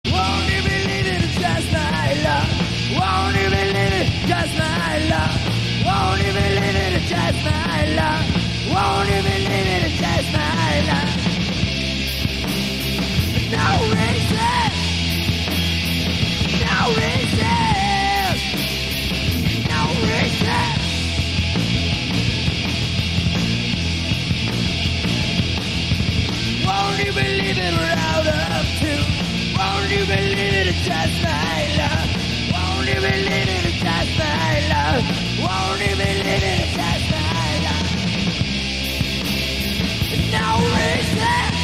Exemple d'un enregistrement Soundboard (Extrait audio)